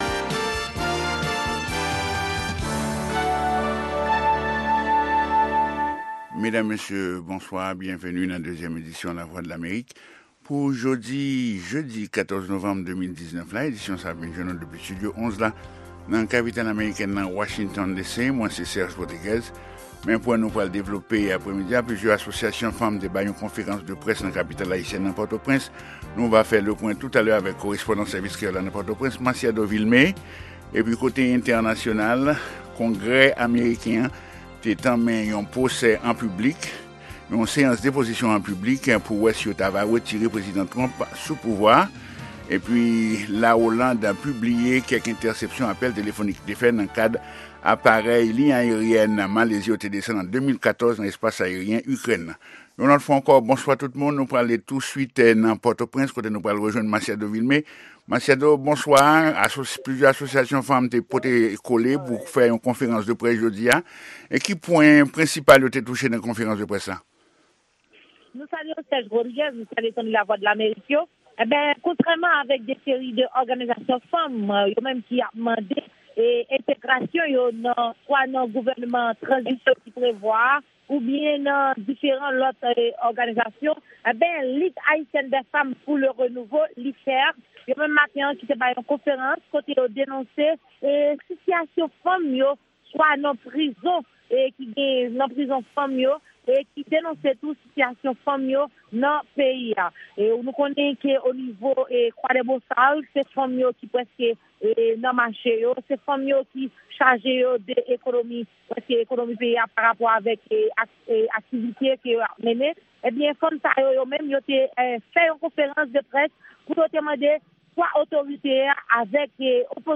Se 2èm pwogram jounen an, avèk nouvèl tou nèf sou Lèzetazini, Ayiti ak rès mond la. 2 fwa pa semèn (mèkredi ak vandredi) se yon pwogram lib tribin "Dyaloge ak Etazini", sou Ayiti oubyen yon tèm enpòtan konsènan Lèzetazini ou rejyon Amerik Latin nan.